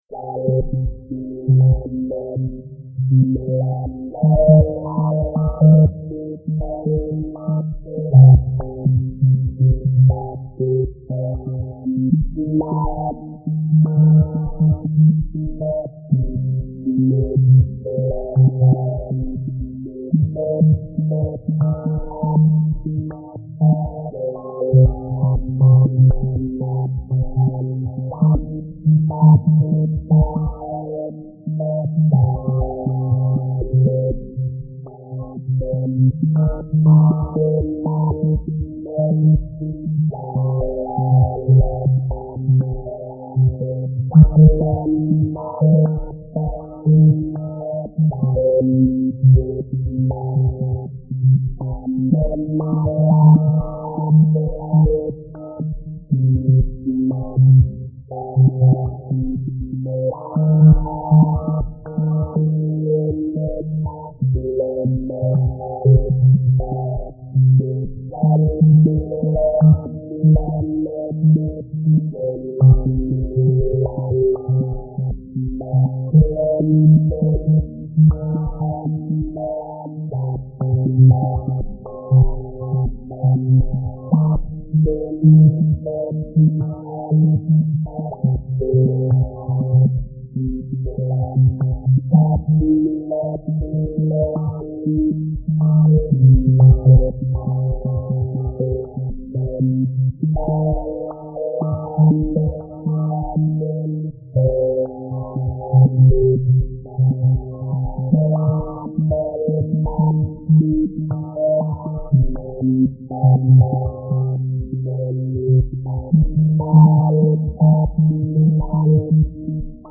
ノイズも多少入ってます。
音源は基本的にハード音源のSc-8850です。